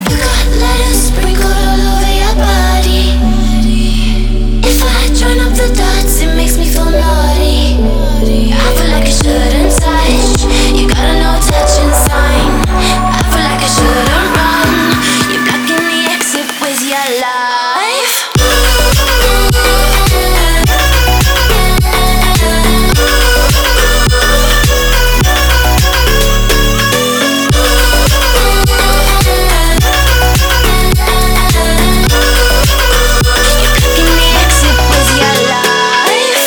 • Качество: 320, Stereo
Electronic
Midtempo
красивый женский голос
house